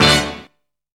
GREY STAB.wav